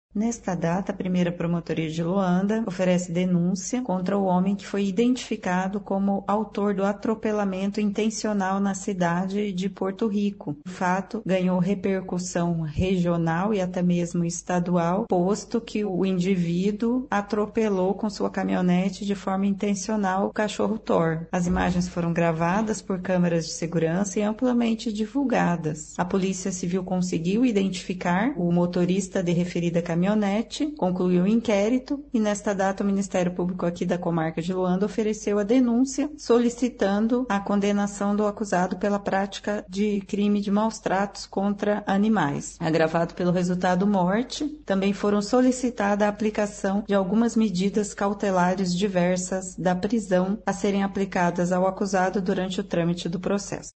Na denúncia, o Ministério Público pede uma indenização para a tutora do animal que ficou abalada emocionalmente. Ouça o que diz sobre o caso a promotora de Justiça Vera de Freitas Mendonça: